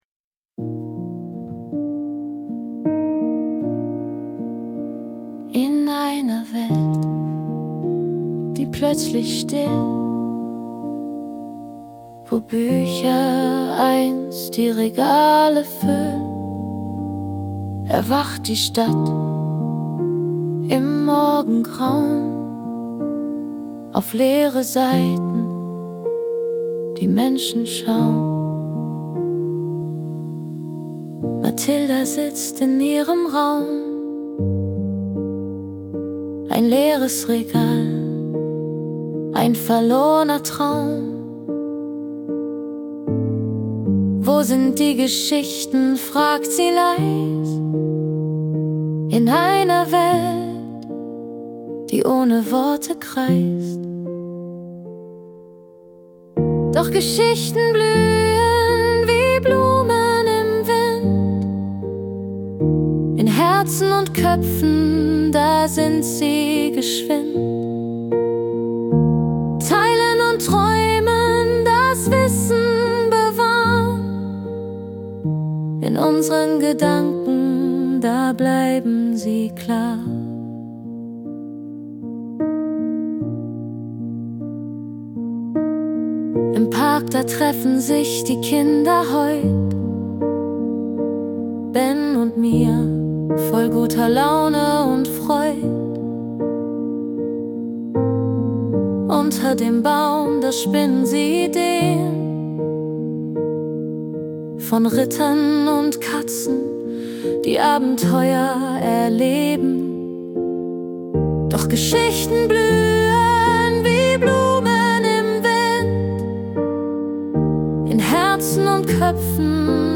Das Schlaflied zur Geschichte
AI Music